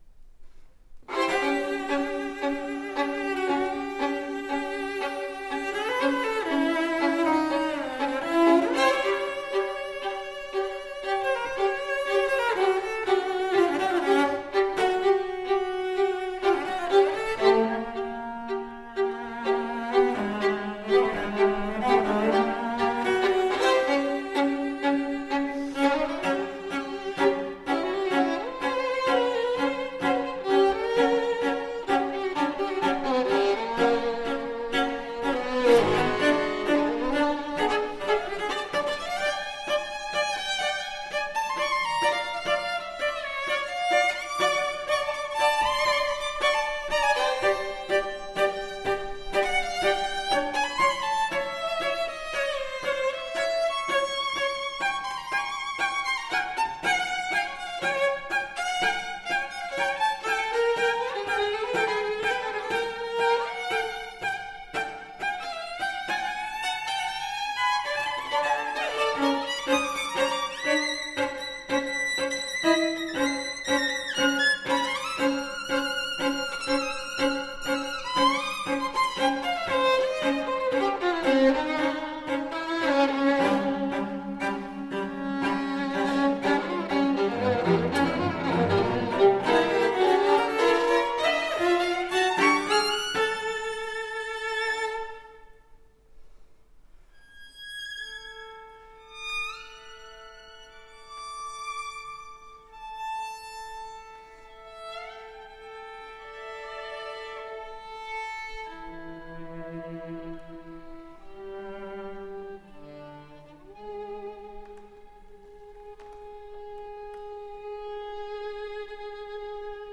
Artist Faculty Concert recordings - July 11, 2014 | Green Mountain Chamber Music Festival
violin
cello
Matt Haimovitz, cello